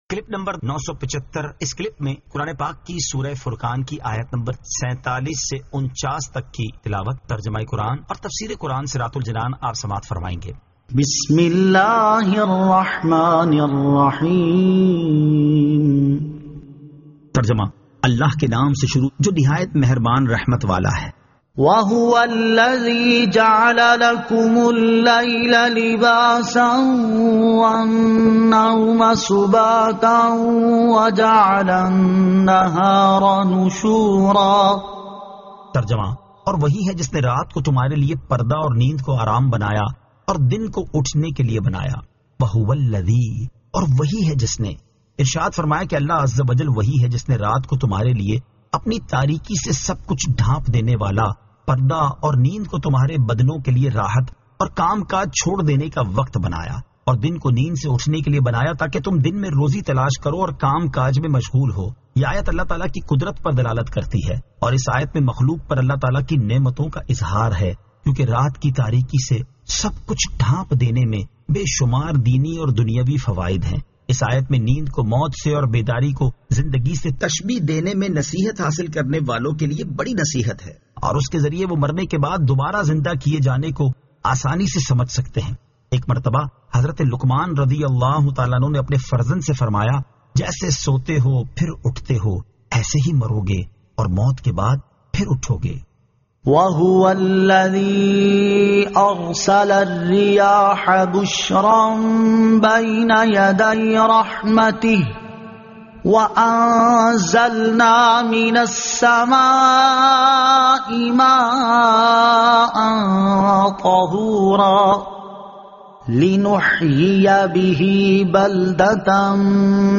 Surah Al-Furqan 47 To 49 Tilawat , Tarjama , Tafseer